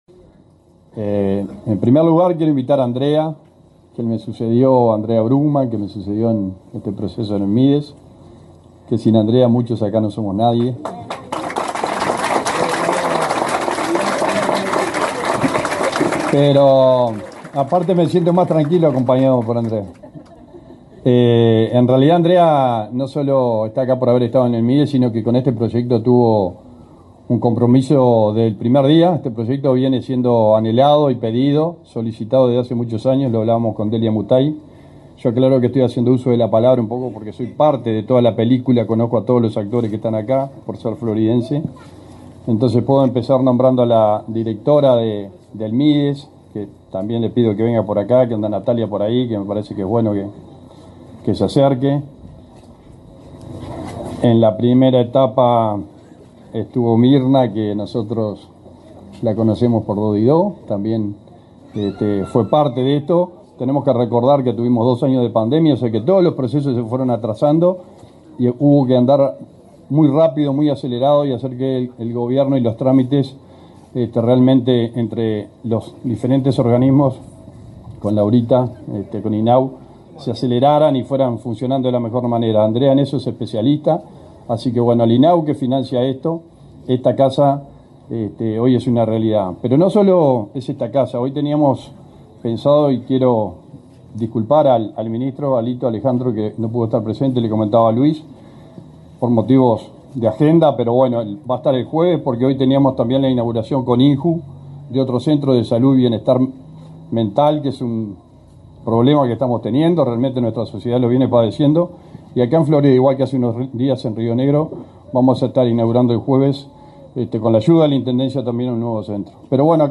Palabras de autoridades en local de INAU en La Cruz, Florida
El subsecretario del Ministerio de Desarrollo Social, Arturo Torres; la directora de Cuidados de esa cartera, Florencia Krall, y el presidente del